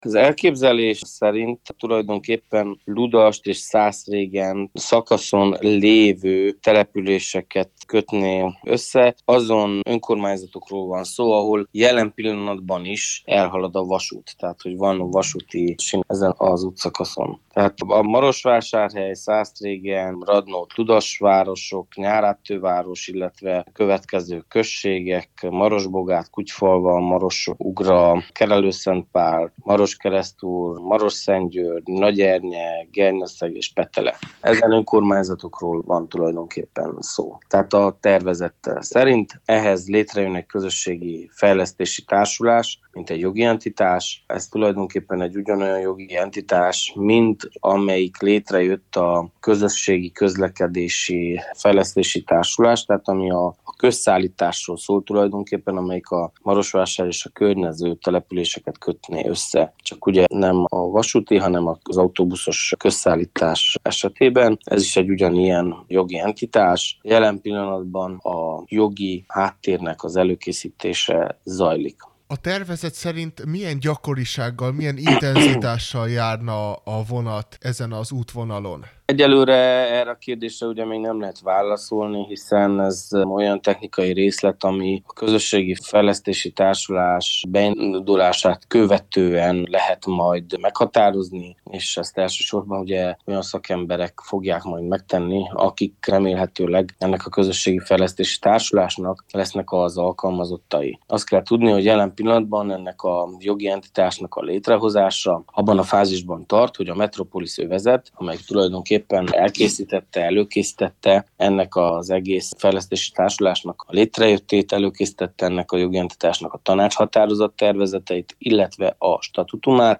mondta Kovács Mihály Levente, a Maros Megyei Tanács alelnöke